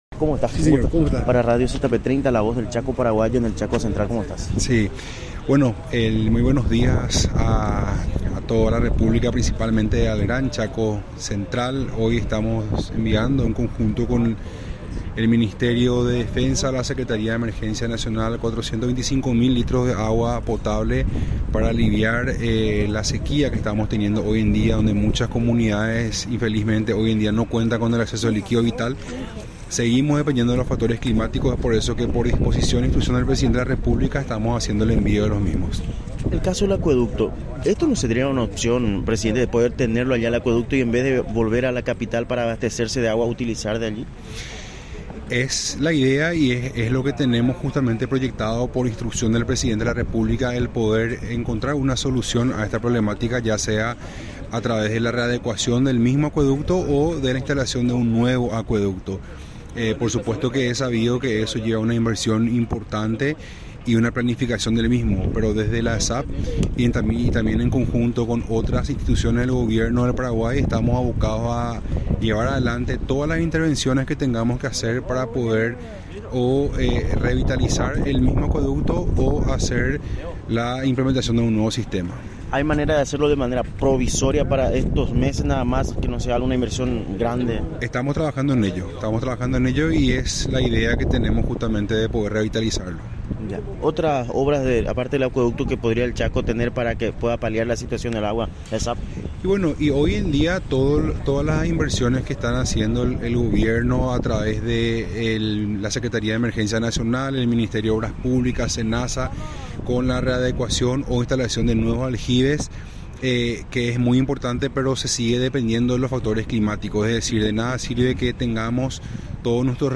Entrevistas / Matinal 610
Estudio Central, Filadelfia, Dep. Boquerón